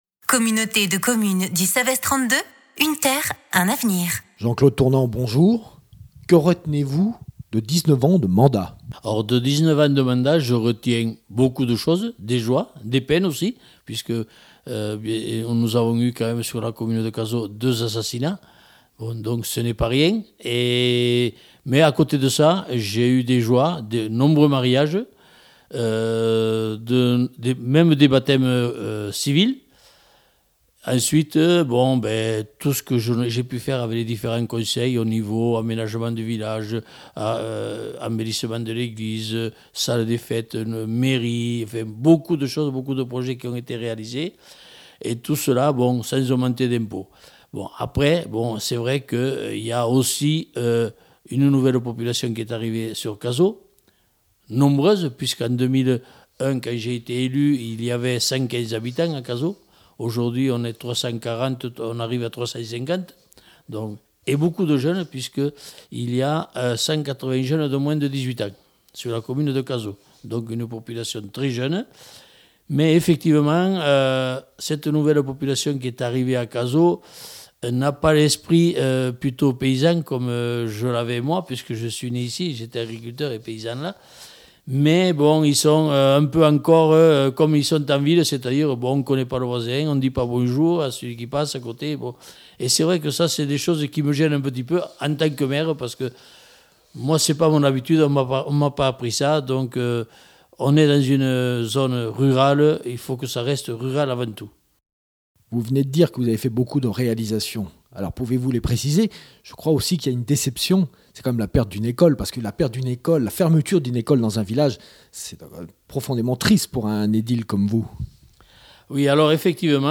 À l’heure où la retraite municipale a sonné, nous avons recueilli leurs témoignages.